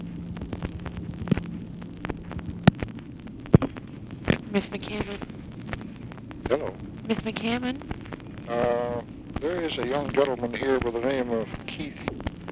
Conversation with UNIDENTIFIED MALE
Secret White House Tapes | Lyndon B. Johnson Presidency